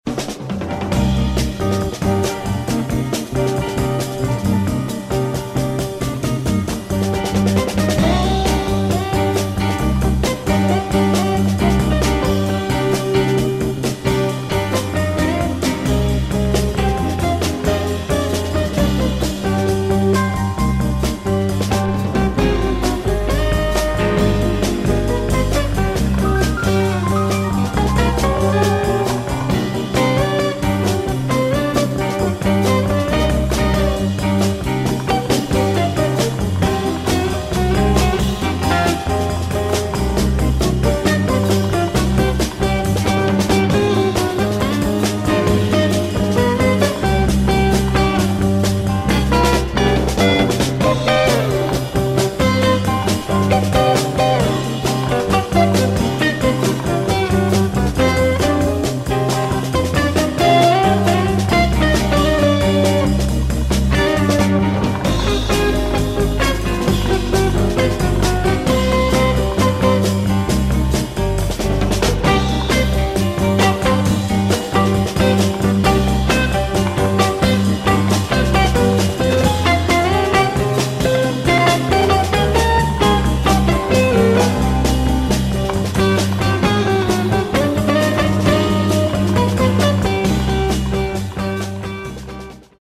One of the best kept secrets in psyche!